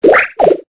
Cri de Doudouvet dans Pokémon Épée et Bouclier.